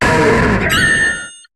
Cri de Bastiodon dans Pokémon HOME.